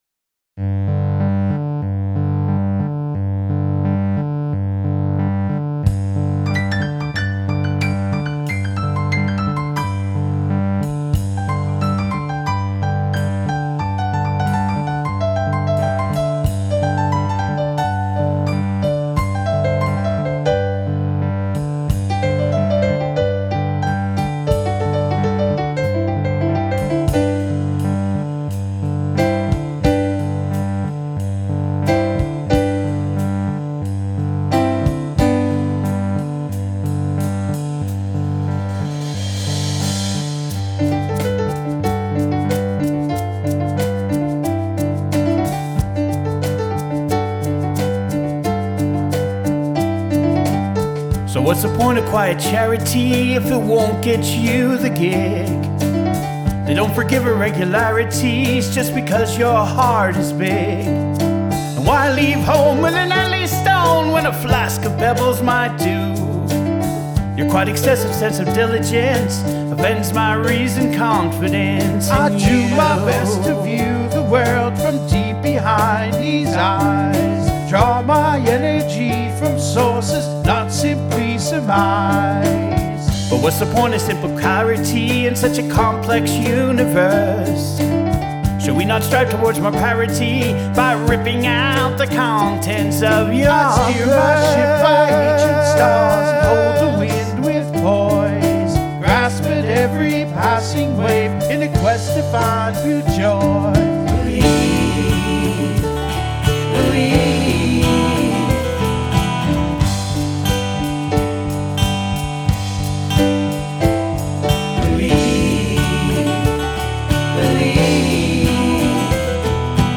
lead vocals, piano, keyboards, acoustic guitar
backing vocals
drums, percussion